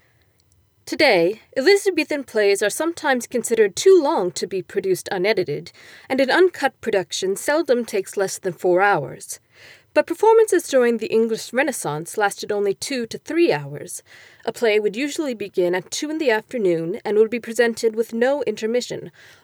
That tiny whine in the background of my post is The Yeti Curse or Frying Mosquitoes.